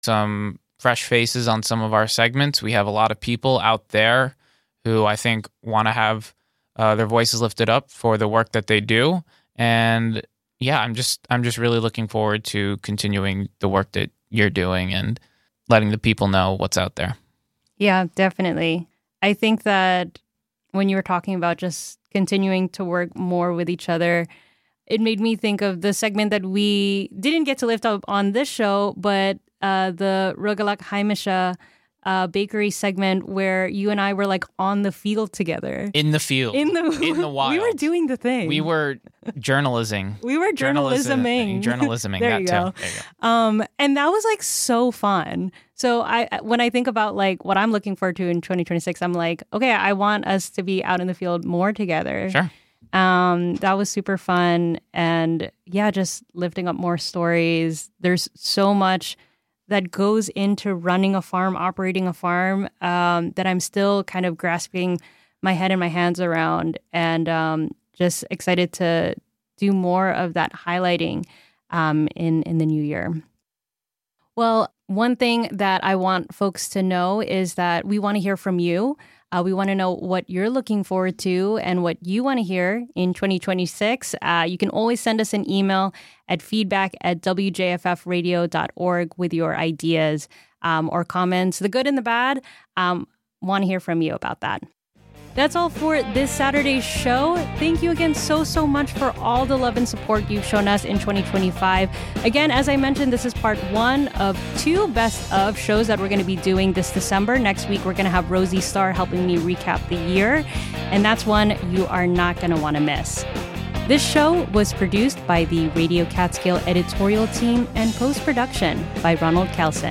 a personality profile show